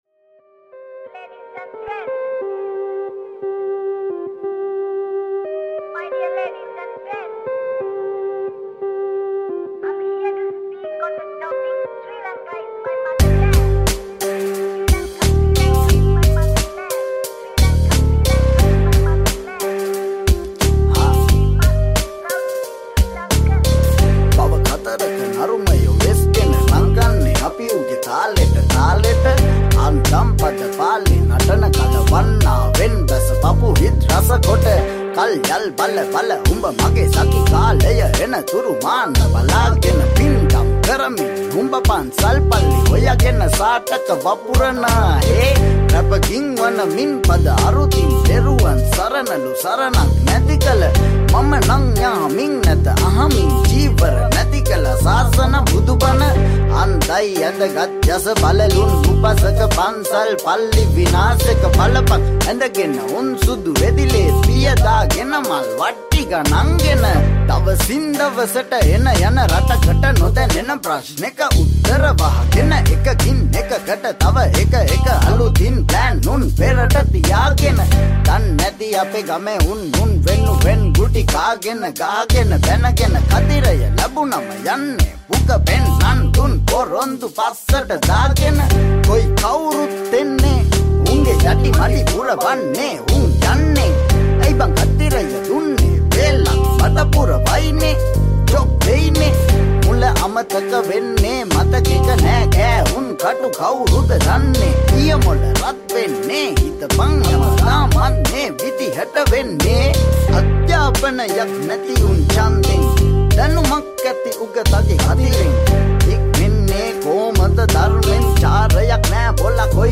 Sri Lankan remix
Rap